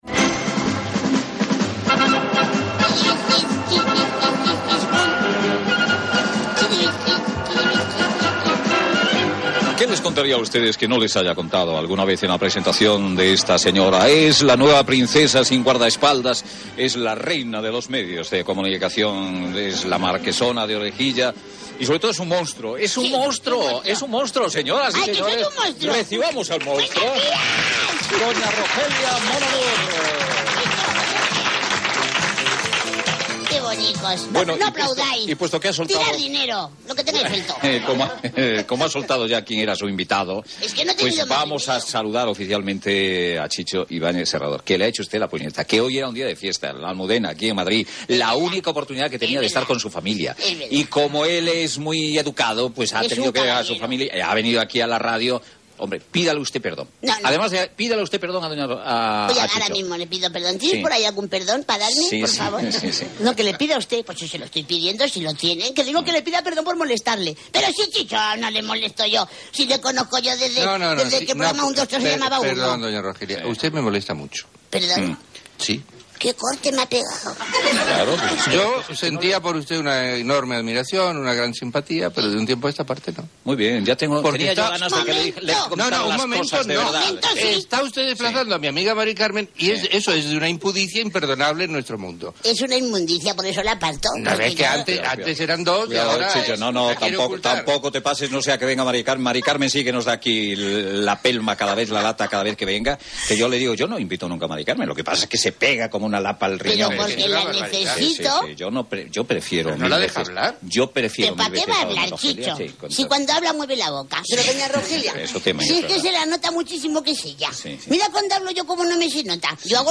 0cbfc00a4a9ce79ee878396ac1d1f52bf24902fa.mp3 Títol Onda Cero Radio Emissora Onda Cero Barcelona Cadena Onda Cero Radio Titularitat Privada estatal Nom programa Protagonistas Descripció "Doña Rogelia mon amour". Entrevista al realitzador de televisió Chicho Ibáñez Serrador , qui parla del programa de TVE "1,2,3 Responda otra vez" Gènere radiofònic Info-entreteniment